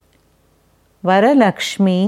Varalakschmi (Sanskrit Yoga Audio Wörterbuch)